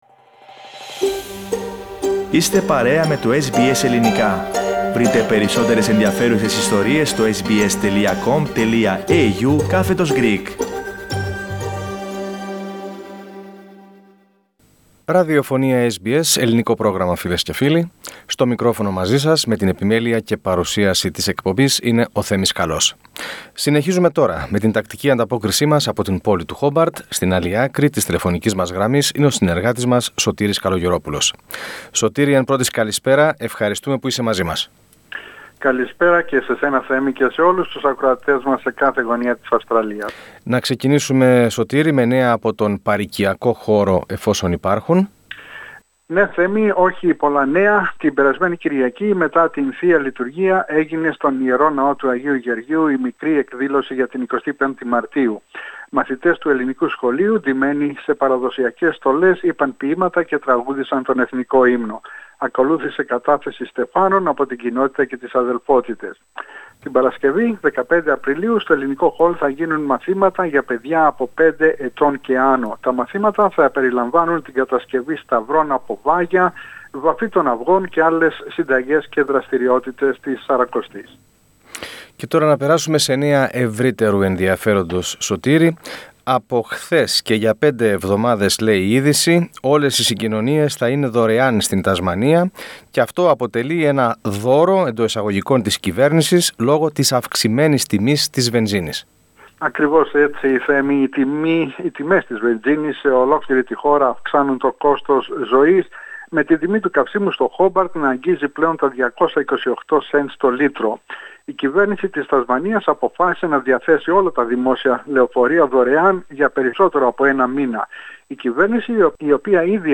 Ο εορτασμός της 25ης Μαρτίου στο Χόμπαρτ, οι δωρεάν συγκοινωνίες που προσφέρει για πέντε εβδομάδες η πολιτειακή κυβέρνηση, το ράλι Targa Tasmania και η κατάσχεση μεγάλης ποσότητας κοκαΐνης, αξίας άνω $6 εκ., είναι τα θέματα της σημερινής ανταπόκρισης από την πρωτεύουσα της Τασμανίας.